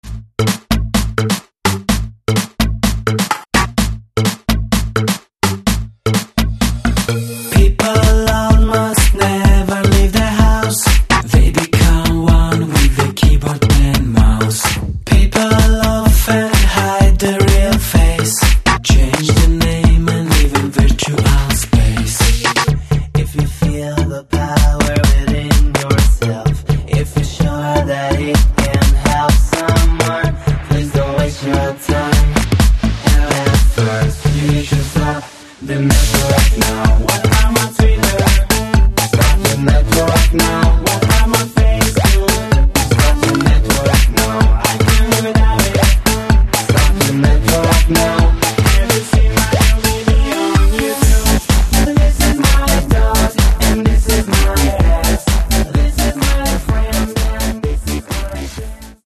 Каталог -> Поп (Легкая) -> Клубная